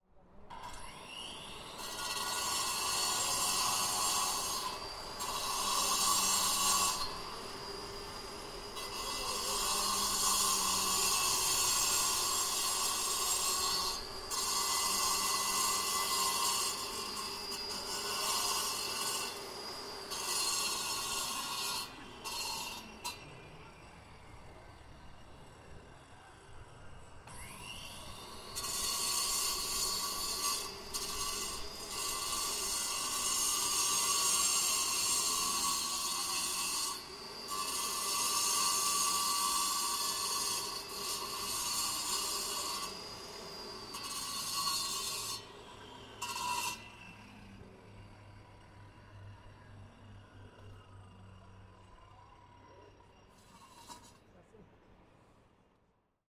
Звук камнедробилки, записанный на кладбище (ими режут памятники)